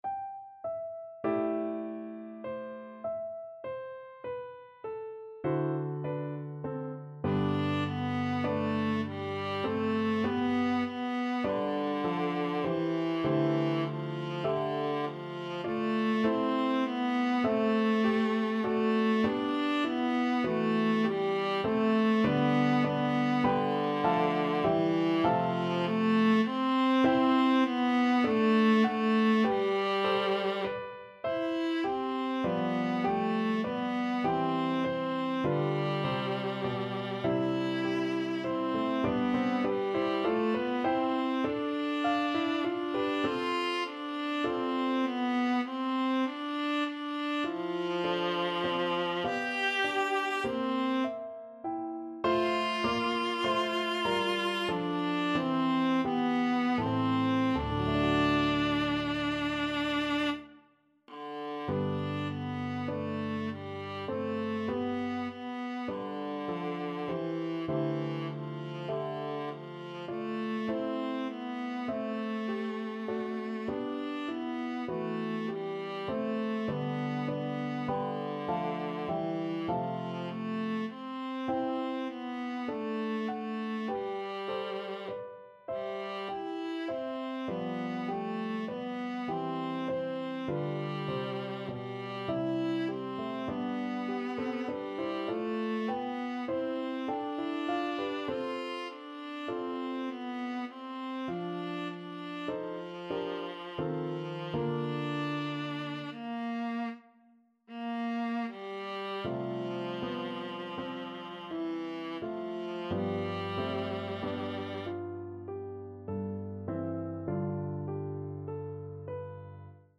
5/4 (View more 5/4 Music)
Classical (View more Classical Viola Music)